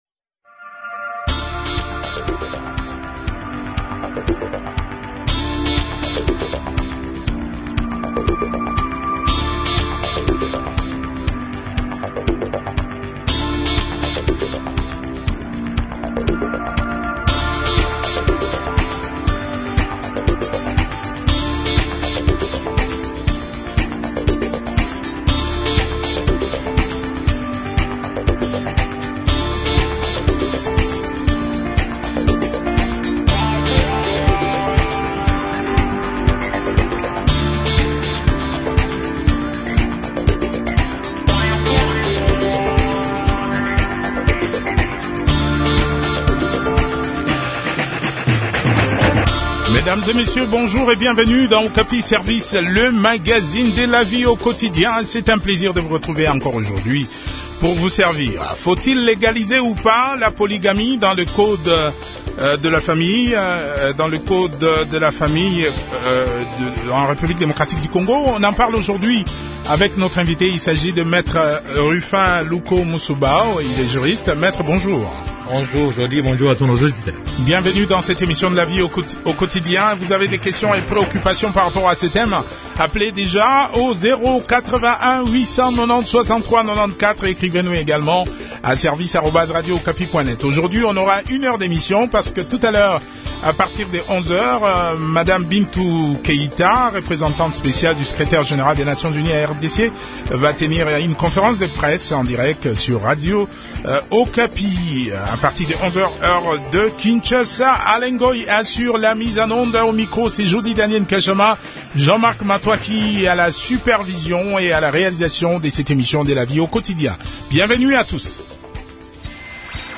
juriste, chercheur et écrivain en droit